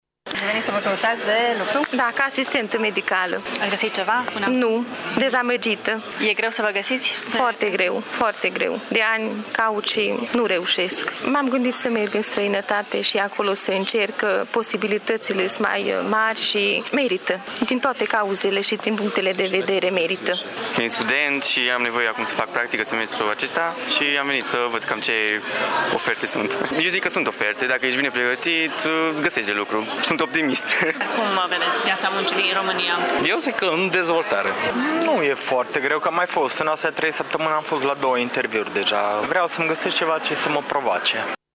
Unii târgumureșeni sunt optimişti că îşi vor găsi un loc de muncă, în timp ce alţii sunt dezamăgiţi şi preferă să plece din ţară: